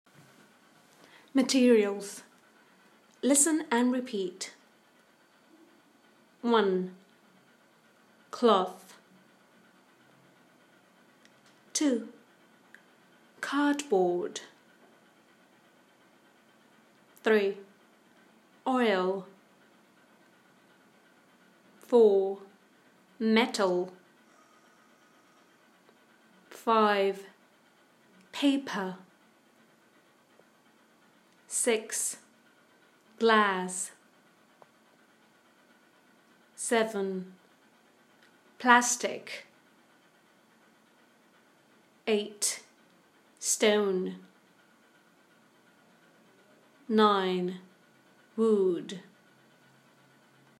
Click PLAY below to see how these materials are pronounced.